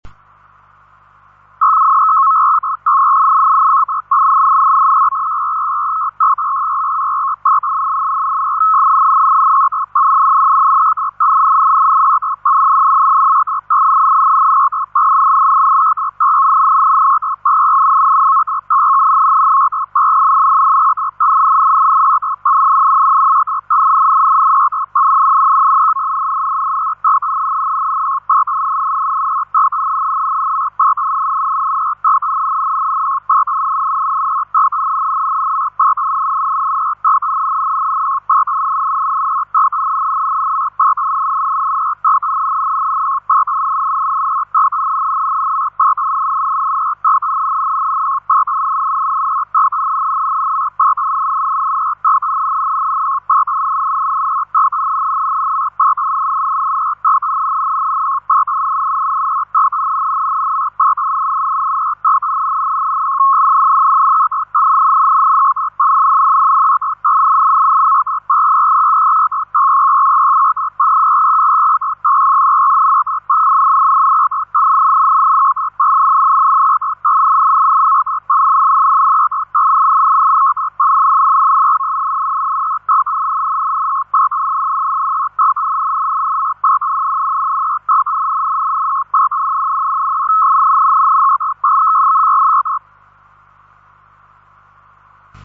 MODI DIGITALI
Il suono di PACTOR I
Il suono di PACTOR I.mp3